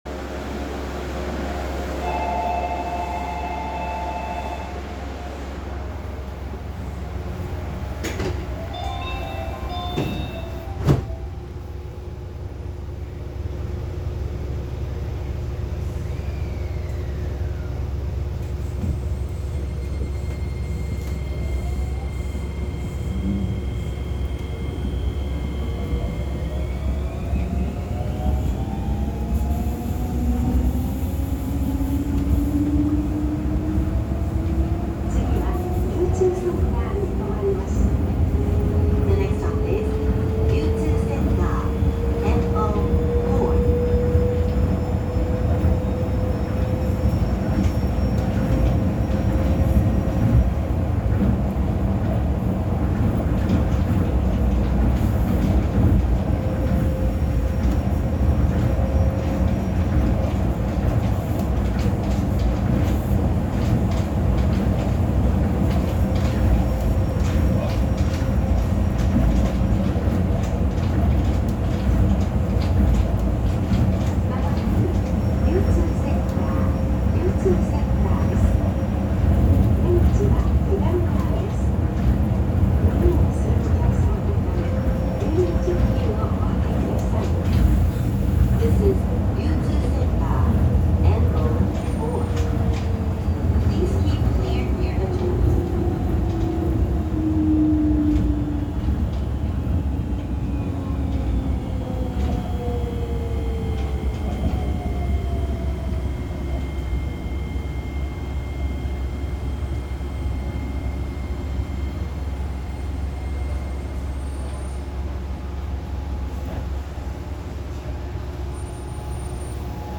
〜車両の音〜
・2000形走行音
【羽田空港線】天王洲アイル→大井競馬場前（2分5秒）
日立製のIGBTインバータを採用しています。ただ、どちらかというと近年の東洋IGBTの音に近い音と言えそうです。